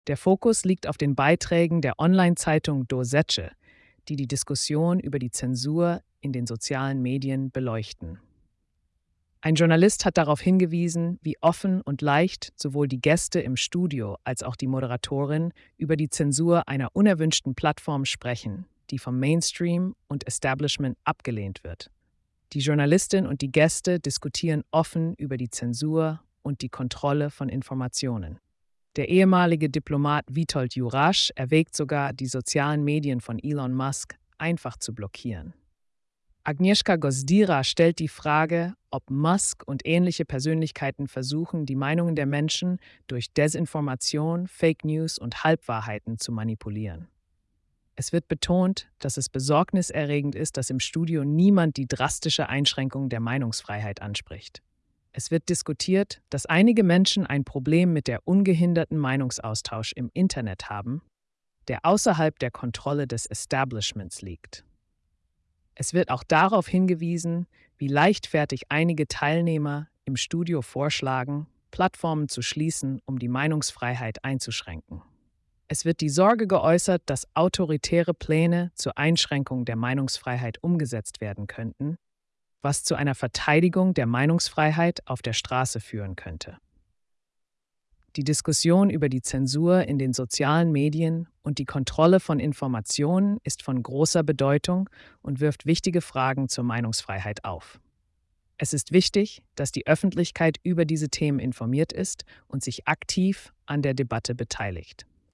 Ein Journalist hat darauf hingewiesen, wie offen und leicht sowohl die Gäste im Studio als auch die Moderatorin über die Zensur einer unerwünschten Plattform sprechen, die vom Mainstream und Establishment abgelehnt wird.